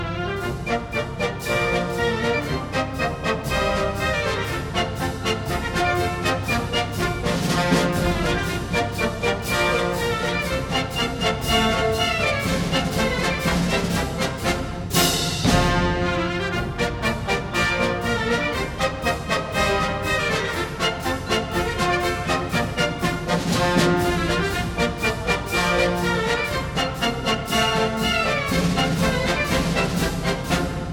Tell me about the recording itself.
1961 stereo recording